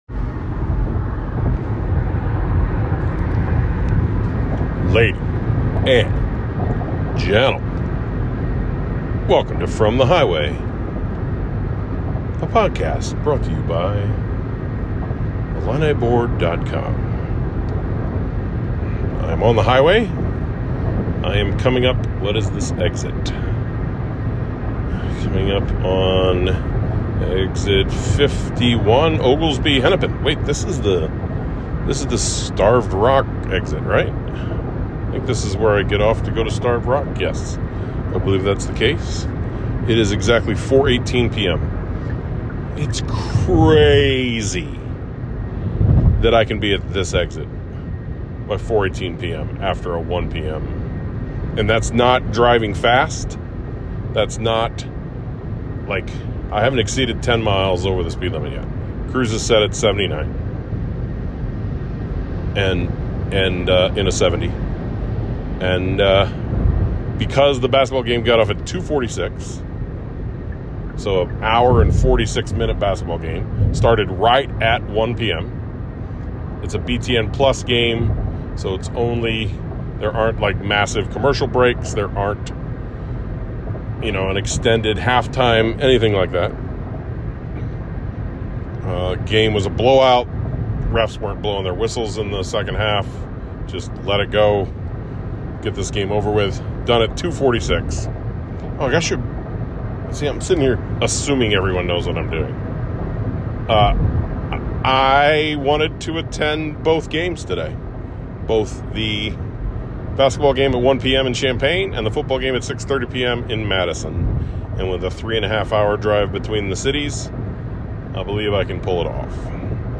We Love No Other From The Highway Before The Wisconsin Game Play episode November 23 1h 29m Bookmarks Episode Description I recorded this on my drive to Wisconsin.